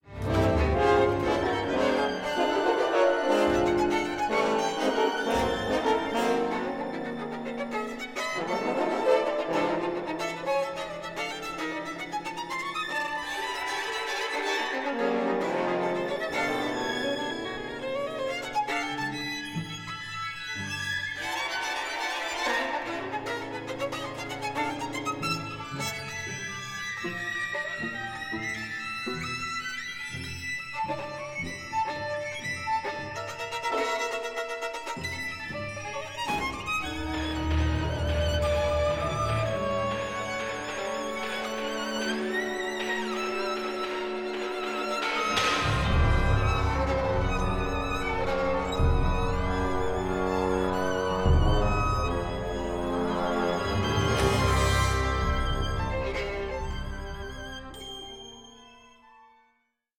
A COSMIC, ADVENTUROUS PAIRING OF VIOLIN CONCERTOS